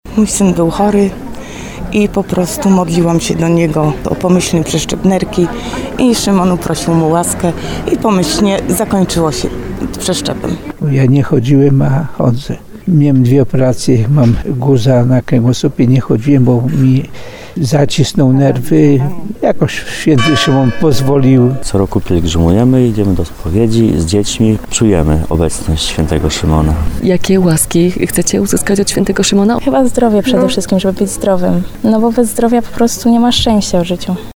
– Co roku pielgrzymujemy, idziemy do spowiedzi razem z dziećmi, czujemy obecność świętego Szymona – mówił kolejny z rozmawiających z naszą reporterką mężczyzn.